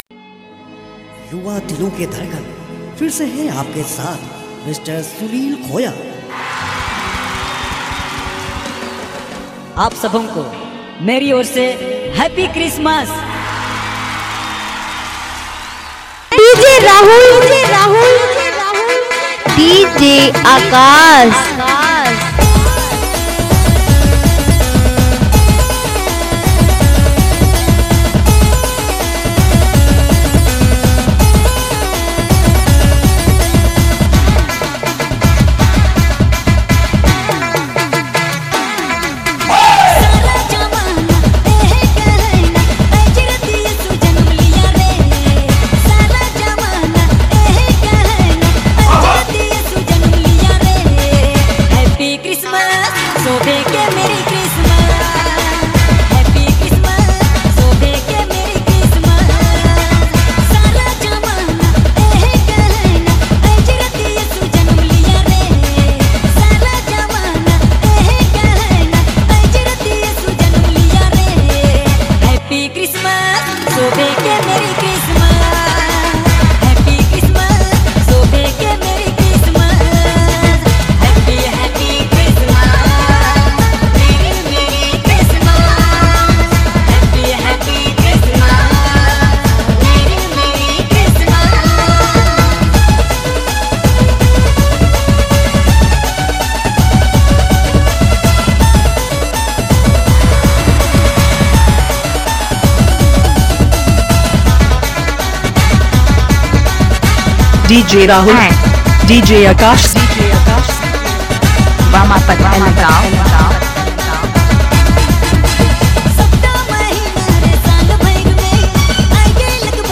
Dj Remixer
Christmas Dhanka Mix Song